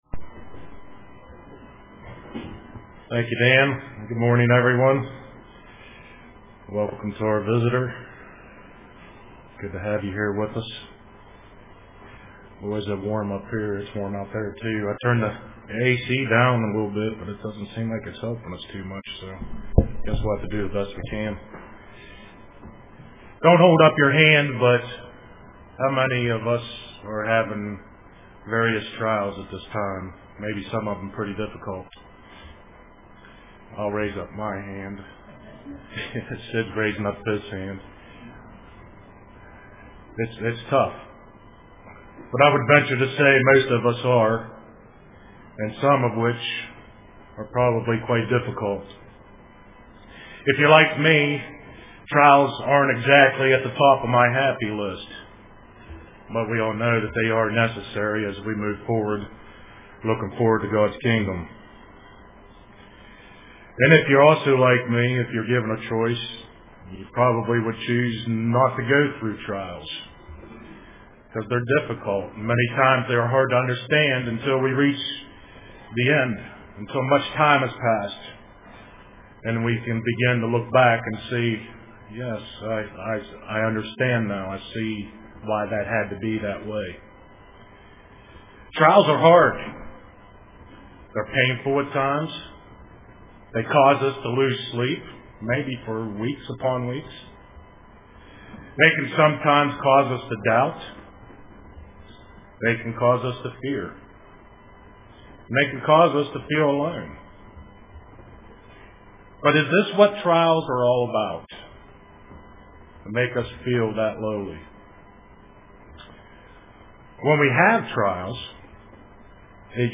Print Trials are a Blessing UCG Sermon Studying the bible?